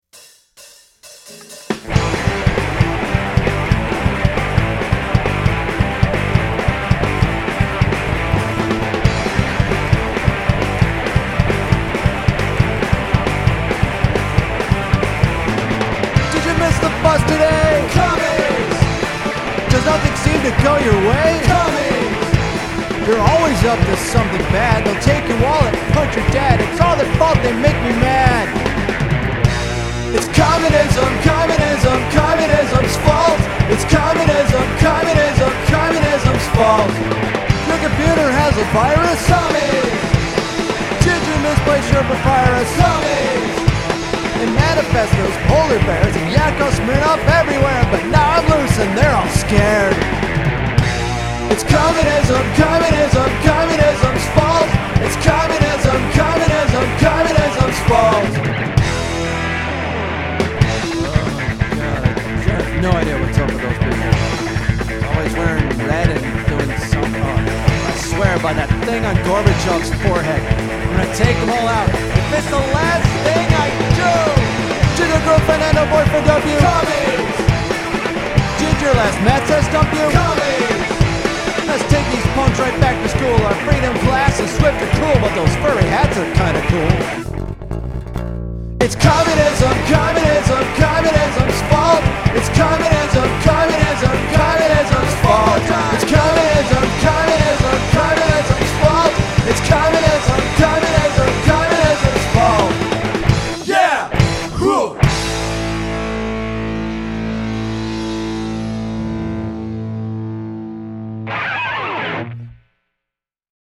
For the first post of 2010, here’s a fun little uptempo number which will be played live by my new band, …Of Doom!
Blah blah blah production and instruments by me.